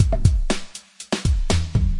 Tag: 回路 节奏 命中 岩石 撞击 120-BPM 打击乐器 节拍 敲击循环 量化 鼓环 常规